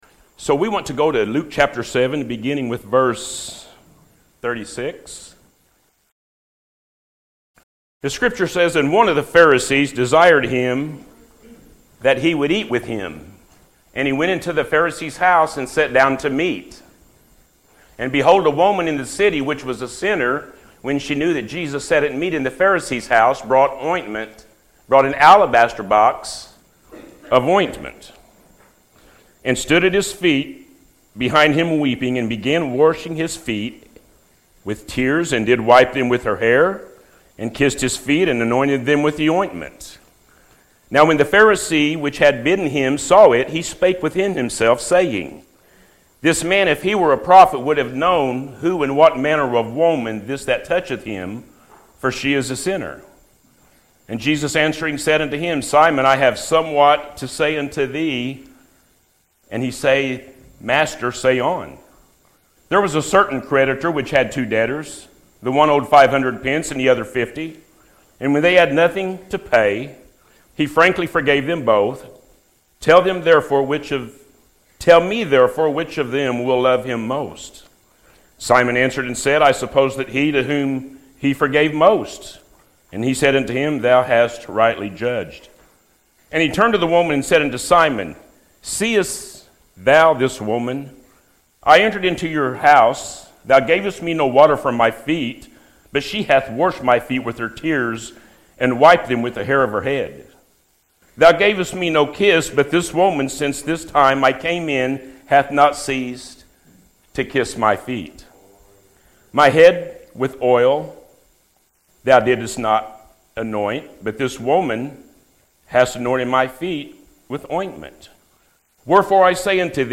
What More Can You Give To Jesus – A.M. Service